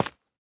1.21.5 / assets / minecraft / sounds / block / tuff / step4.ogg
step4.ogg